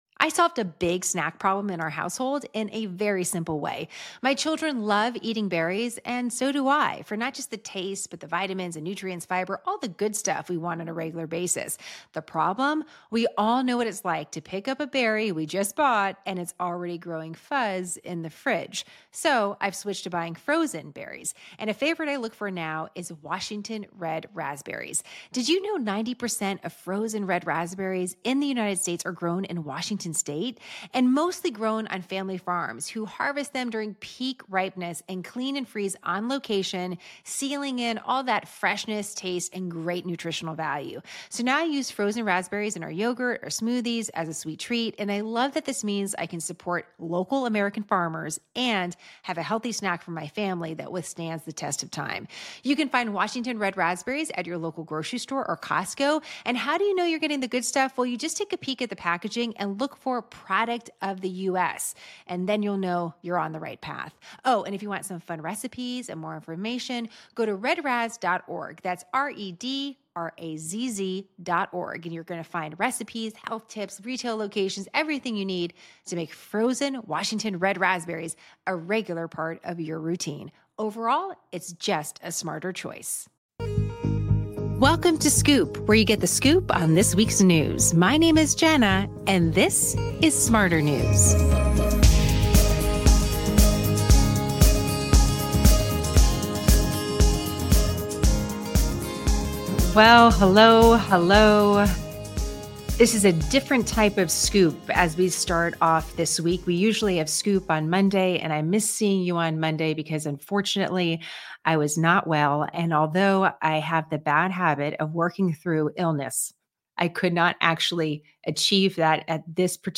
We discuss this and more in our LIVE SCOOP.
What do you think is the best way forward? 45:00: Quick soundbite from a Titanic survivor.